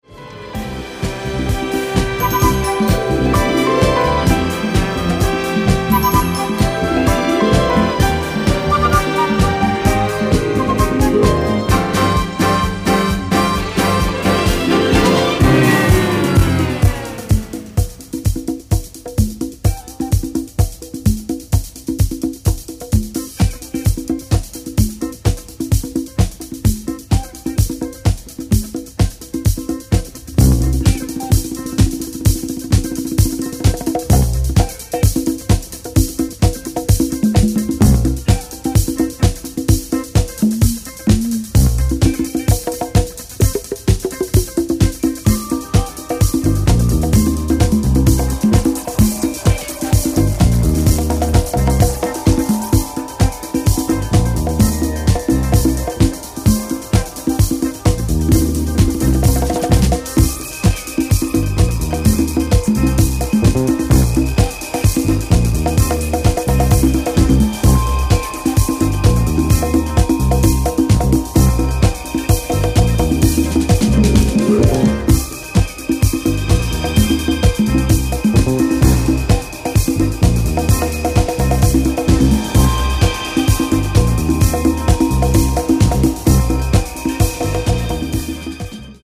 ストリングスやピアノが美しく舞い上がる